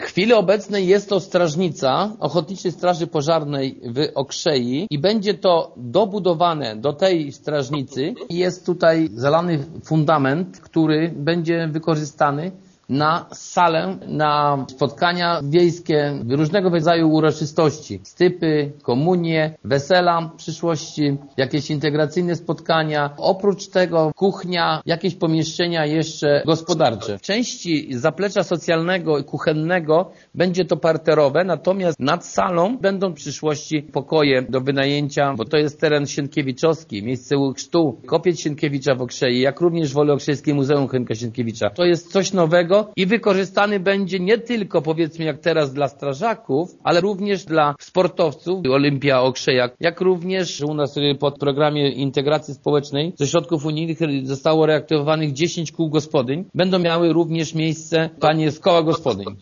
W przyszłości będą z niego korzystać strażacy, członkinie koła gospodyń wiejskich i sportowcy – mówi wójt Jerzy Kędra: